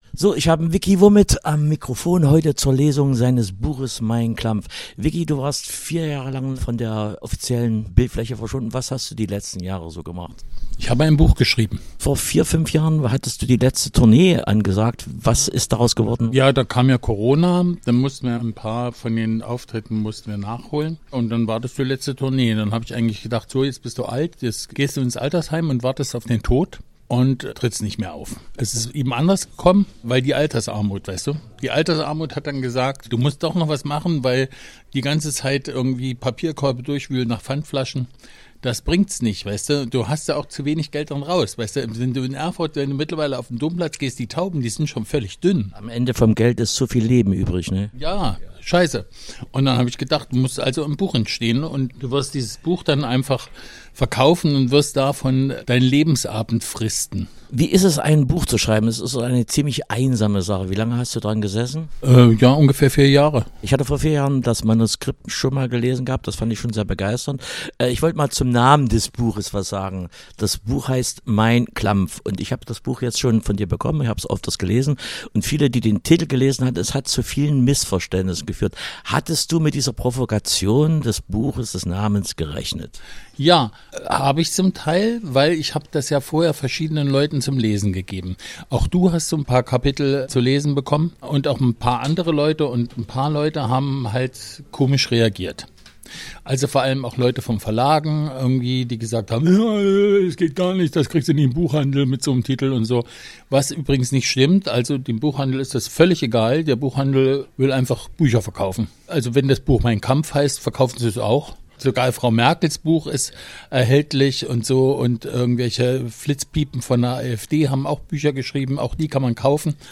Wochenstart: Im Gespräch mit Vicki Vomit ~ Podcasts von RADIO OKJ Podcast